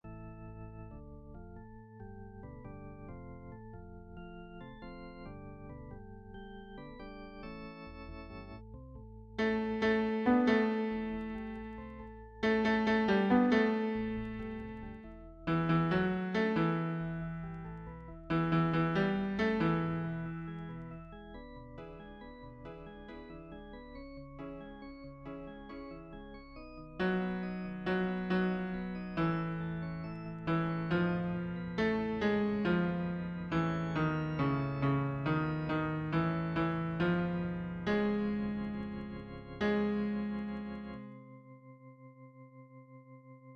Chanté:     S1